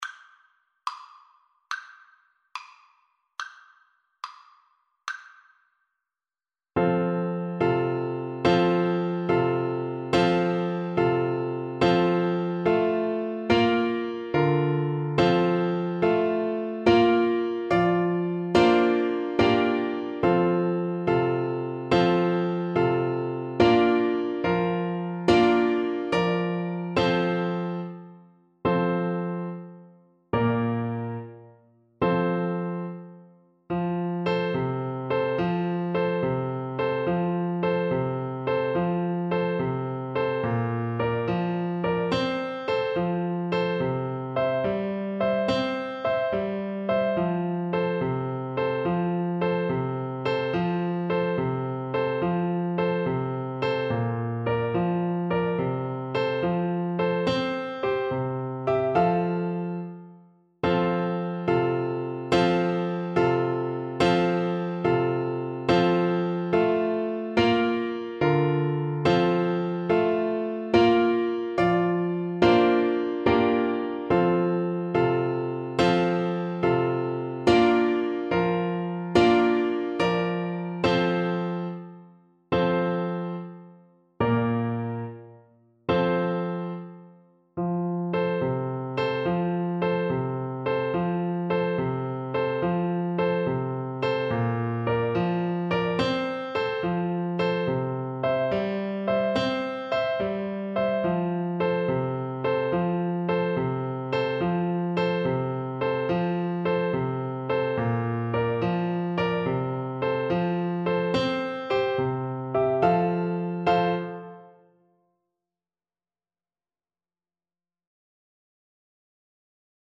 Clarinet version
Allegro moderato .=c.108 (View more music marked Allegro)
6/8 (View more 6/8 Music)
Clarinet  (View more Intermediate Clarinet Music)